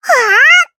Taily-Vox_Casting1_jp.wav